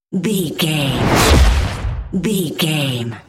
Sci fi whoosh to hit fast
Sound Effects
Atonal
Fast
futuristic
tension
woosh to hit